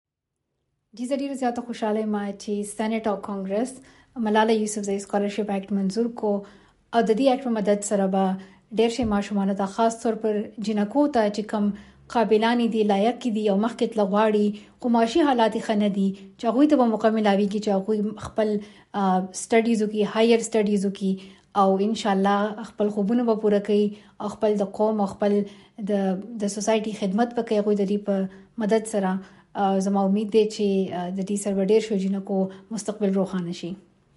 وي او ای ډیوه ته په خپل ريکارډ شوي پېغام کې ملاله وویل: